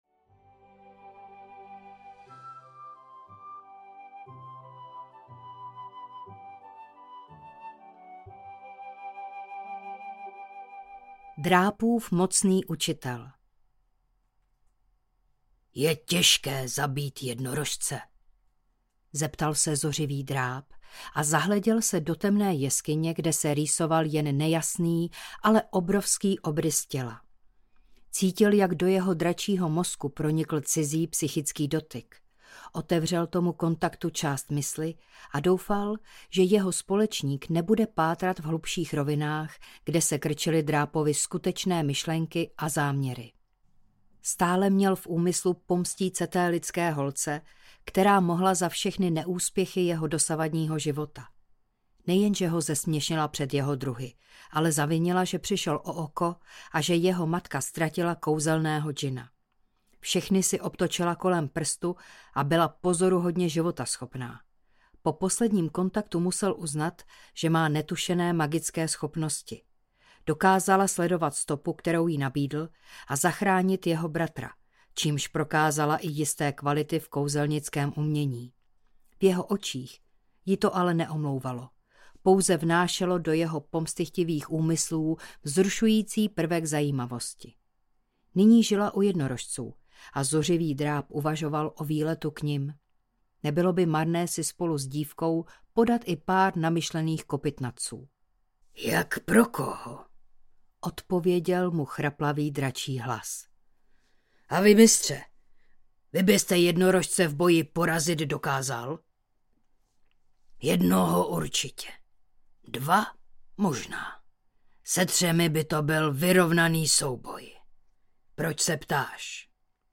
Vrať drakovi, co je jeho audiokniha
Ukázka z knihy